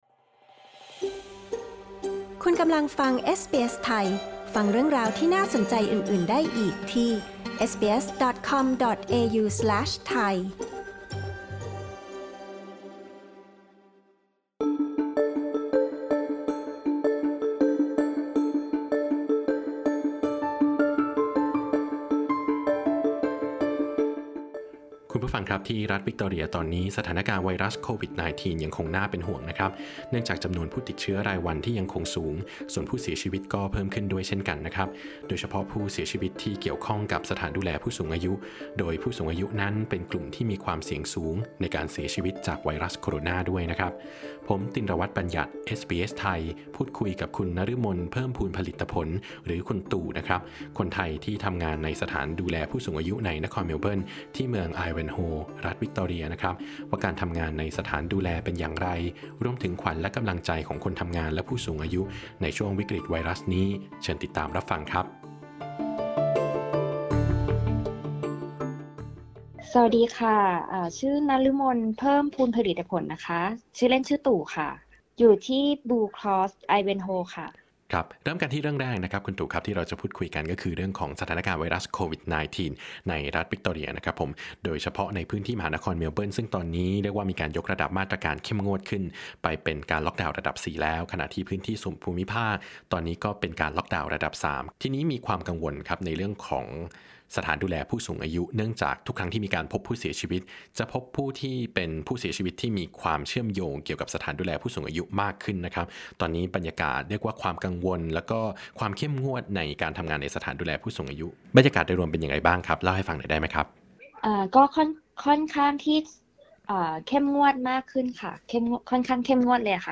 10_aug_intv_virus_aged_care_thai_pod.mp3